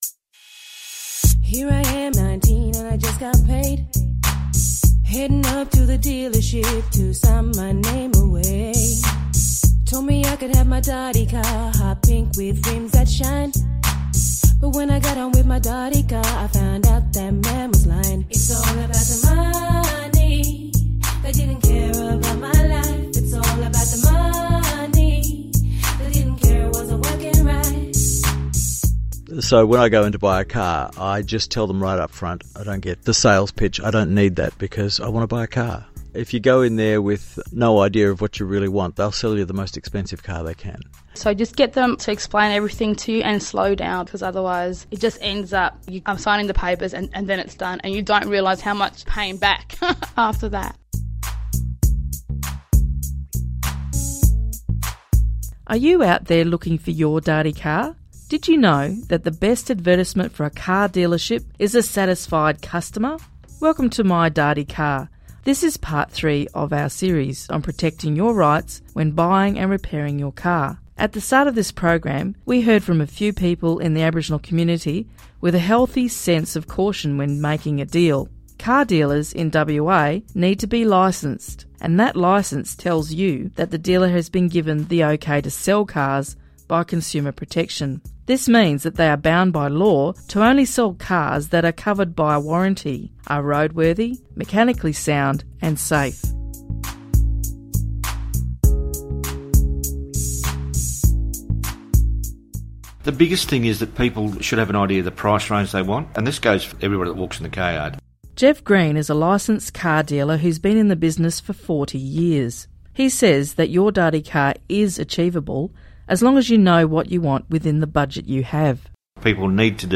The “My Dardy Car” radio series are personal stories and advice from experts about buying and keeping a car. It includes the views of a number of Indigenous consumers plus advice from a car mechanic, car dealer, ‘bush’ mechanic and experienced Consumer Protection staff.